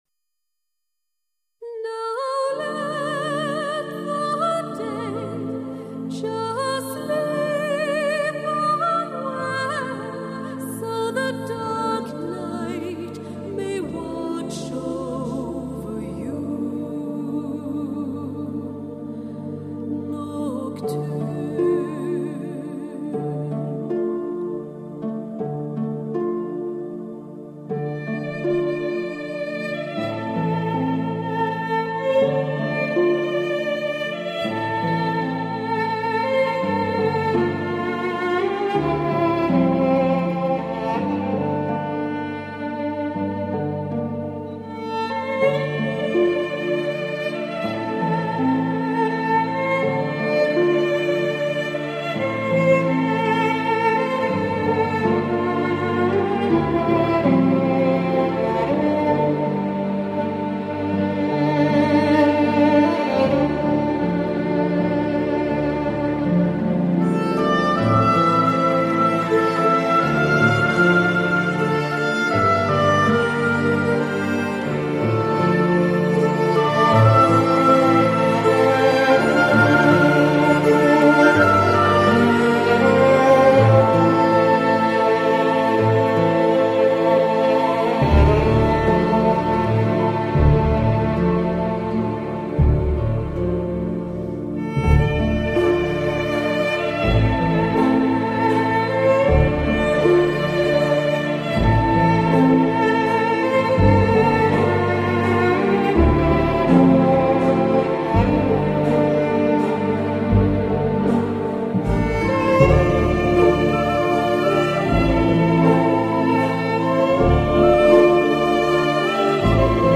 重金属的音质 娓娓动听的弦律 尽情享受天籁之音
DISK（二） 器乐篇